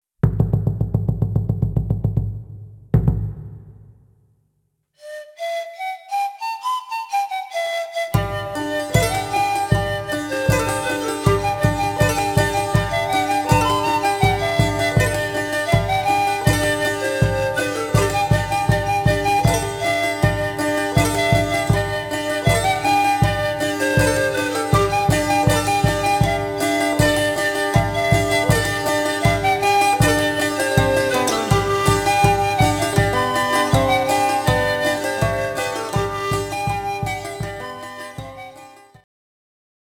フォルクローレミュージックのバンドを組んでいます。
（インストゥルメンタル：ボリビア伝承曲）